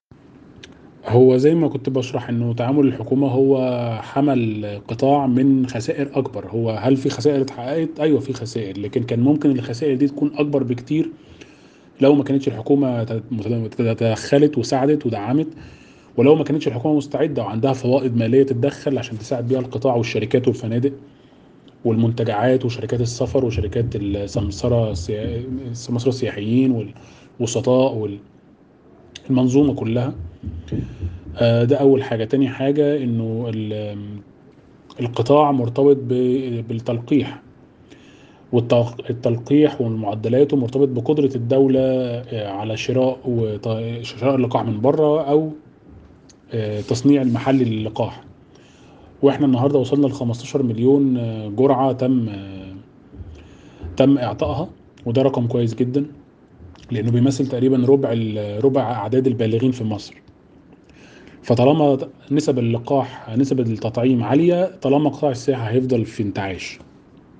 حوار
محلل اقتصادي